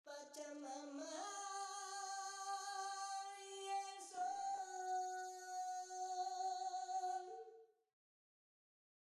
Mit Melodyne erzeuge ich eine dritte Stimme: